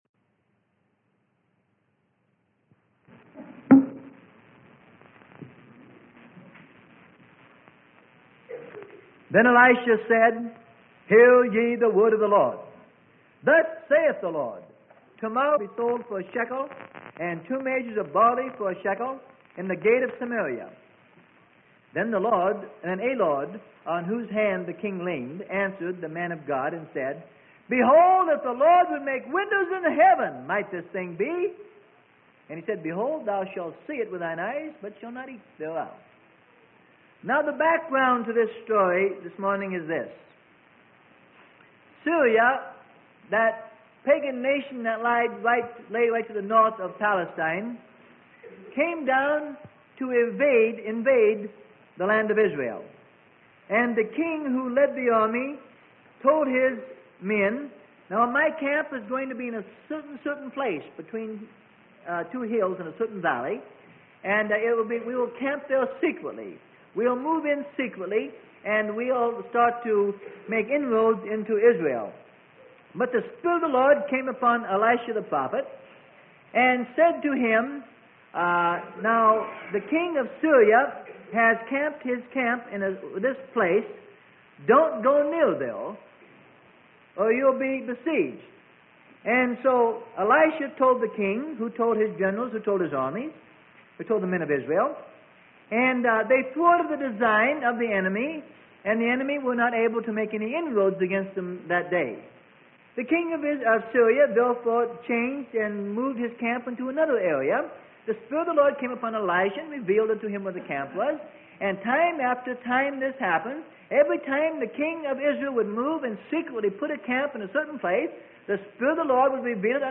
Sermon: Windows of Heaven - Freely Given Online Library